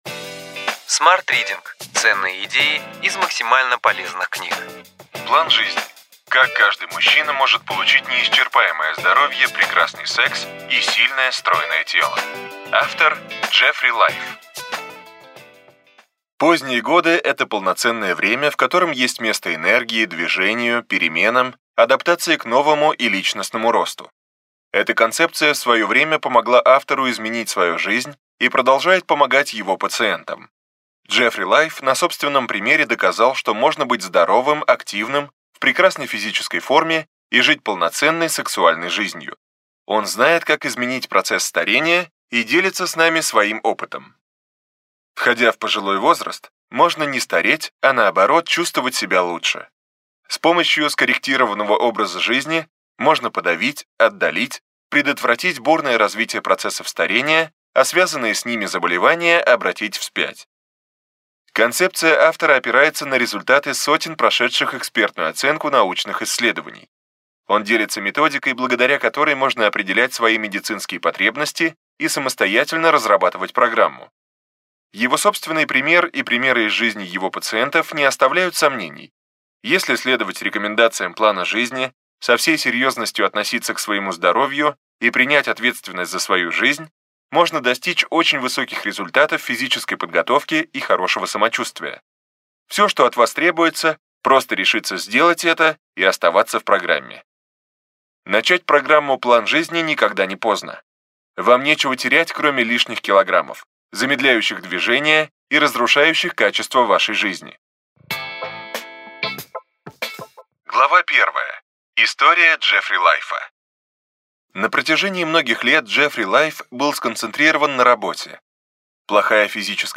Аудиокнига Ключевые идеи книги: План жизни: как каждый мужчина может получить неисчерпаемое здоровье, прекрасный секс и сильное, стройное тело.